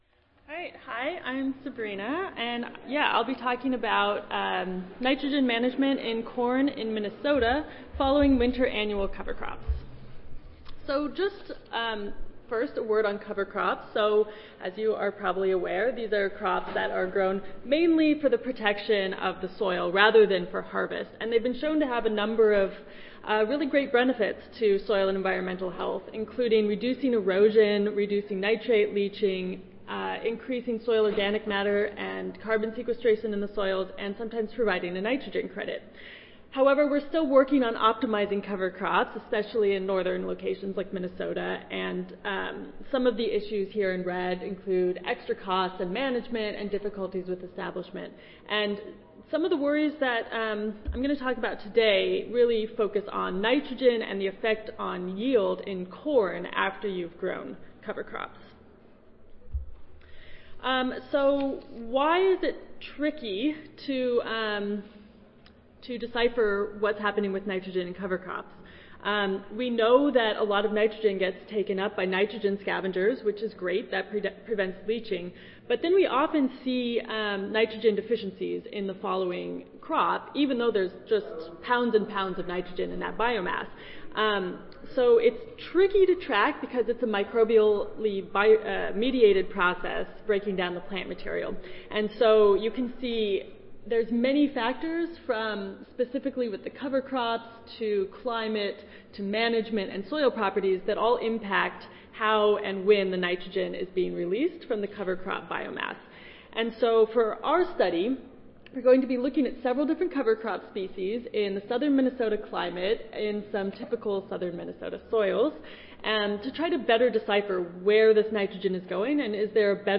Session: Ph.D. Oral Competition I (ASA, CSSA and SSSA International Annual Meetings)
University of Minnesota Audio File Recorded Presentation